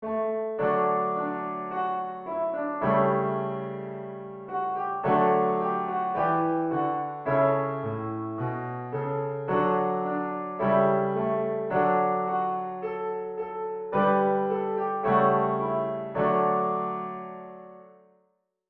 Lower key
Psalm-108-Audio-Lower.wav